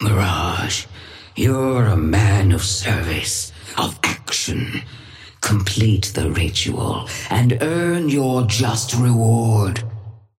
Sapphire Flame voice line - Mirage, you're a man of service.
Patron_female_ally_mirage_start_03.mp3